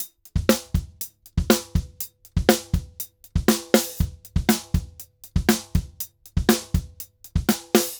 Drums_Salsa 120_2.wav